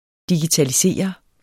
Udtale [ digitaliˈseˀʌ ]